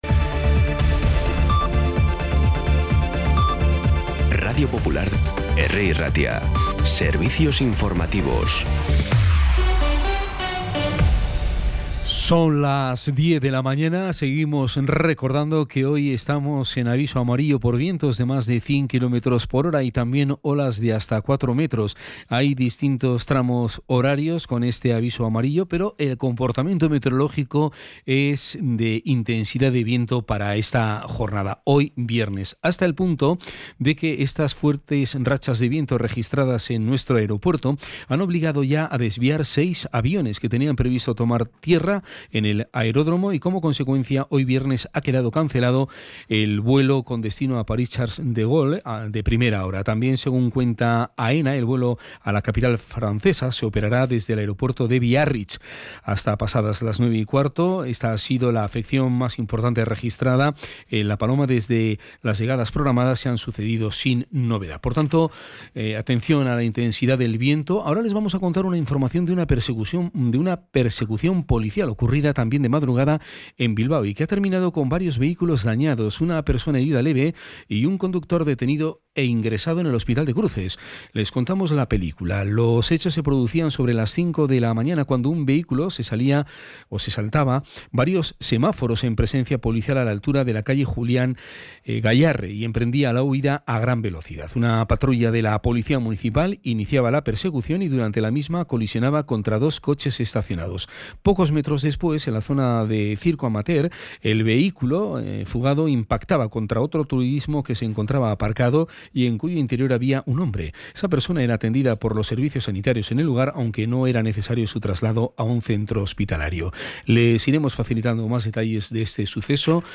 Las noticias de Bilbao y Bizkaia del 23 de enero a las 10
Podcast Informativos
Los titulares actualizados con las voces del día. Bilbao, Bizkaia, comarcas, política, sociedad, cultura, sucesos, información de servicio público.